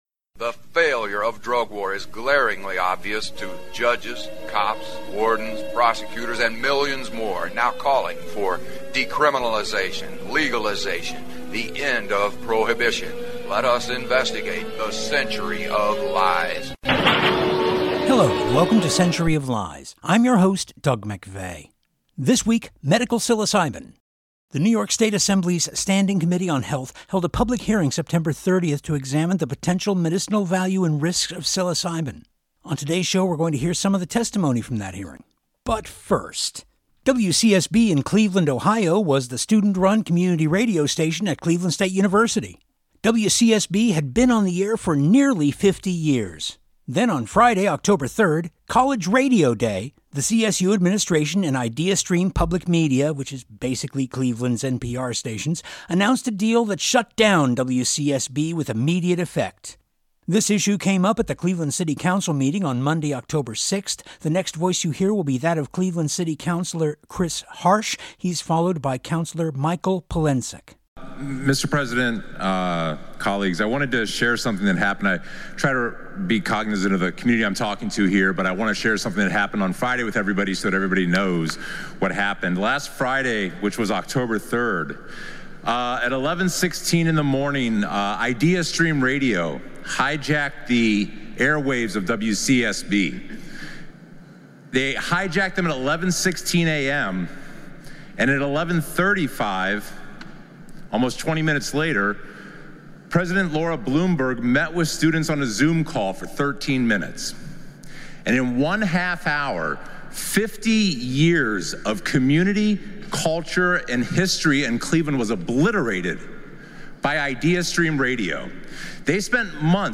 The New York State Assembly’s Standing Committee on Health held a public hearing September 30 to examine the potential medicinal value and risks of psilocybin.